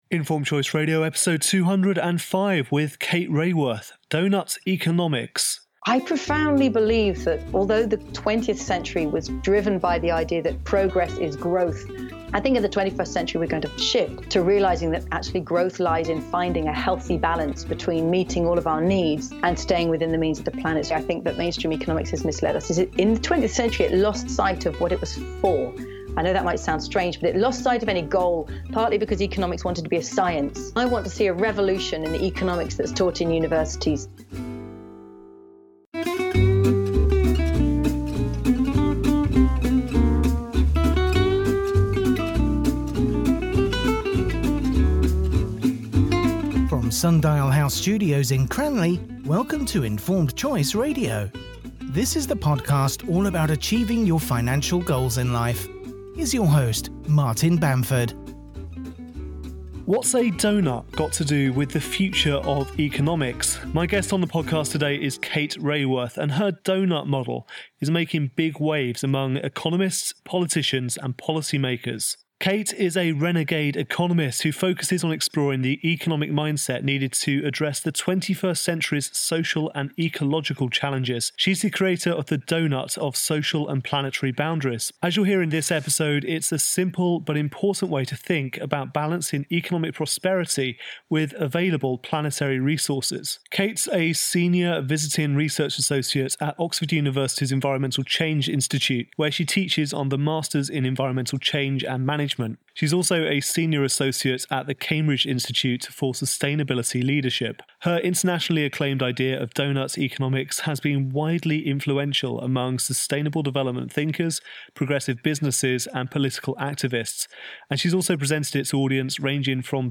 What’s a doughnut got to do with the future of economics? My guest on the podcast today is Kate Raworth and her doughnut model is making big waves among economists, politicians and policy makers.